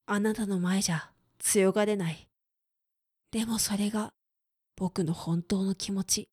クール男性
dansei_anatanomaejatuyogarenai.demosoregabokunohontounokimoti.mp3